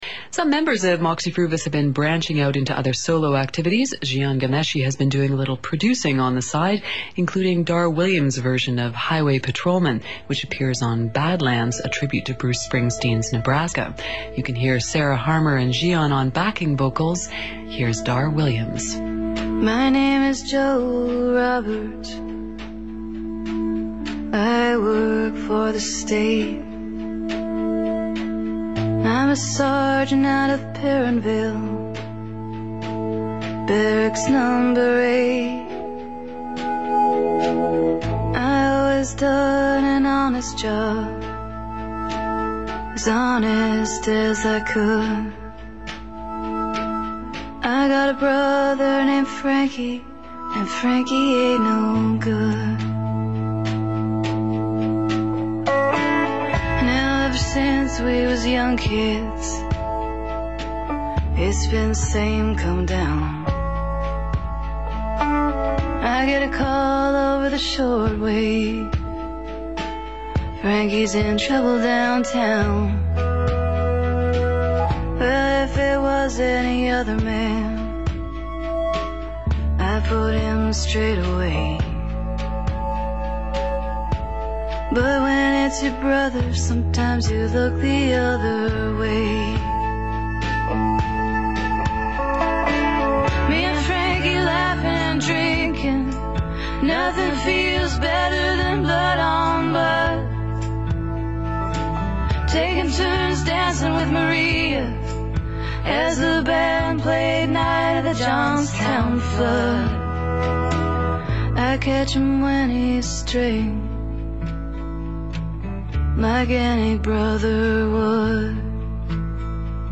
backup vocals